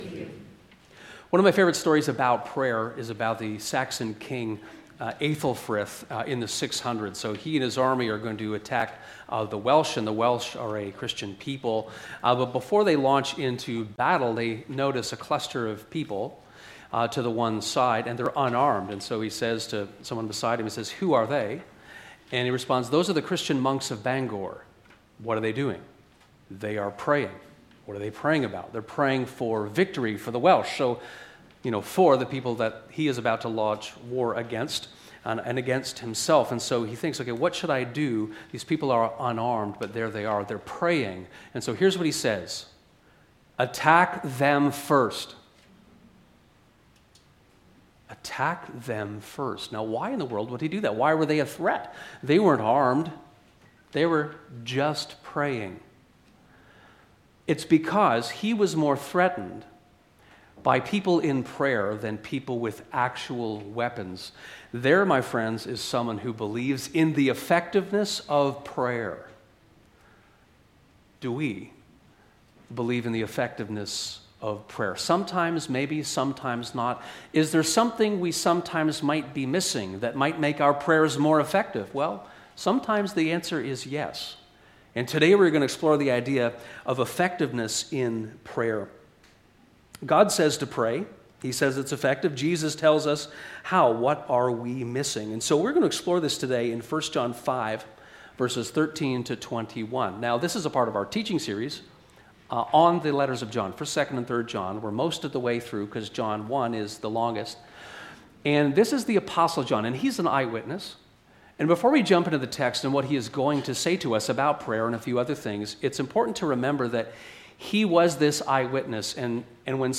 How can I pray more effectively? Sermon